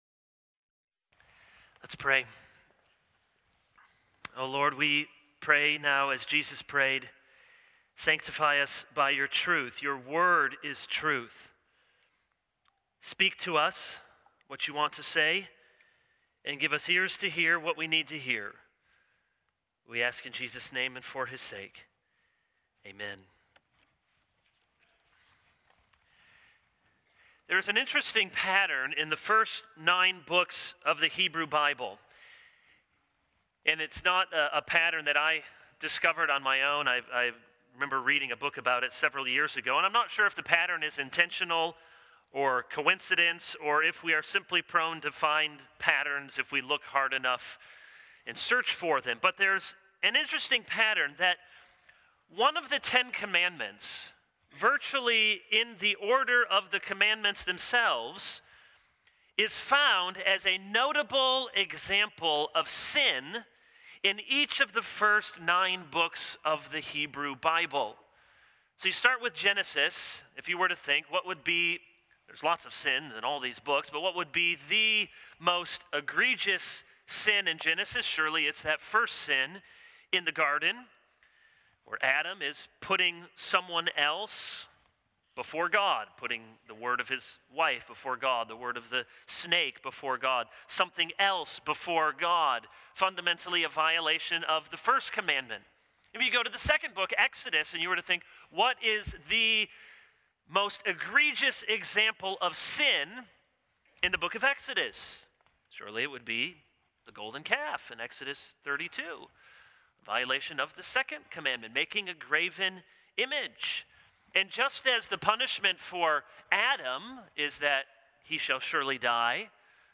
This is a sermon on Exodus 20:16.